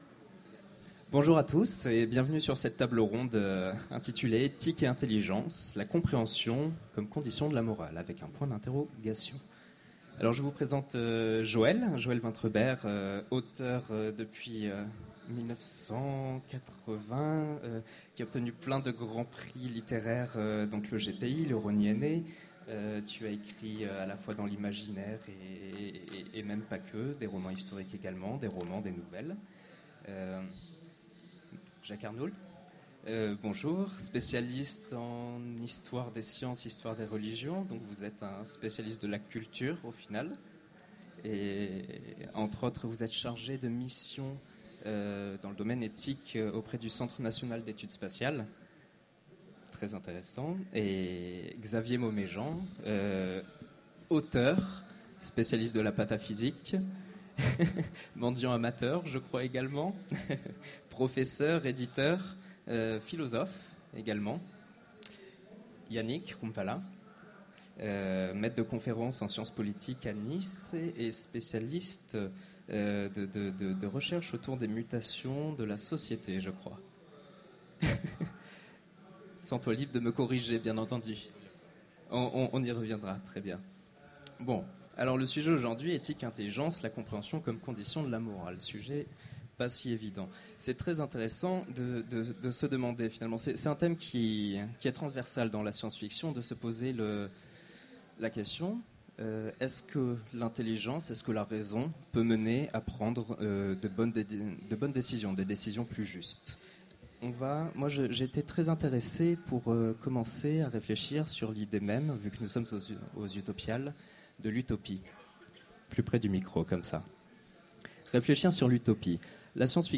Mots-clés Philosophie Conférence Partager cet article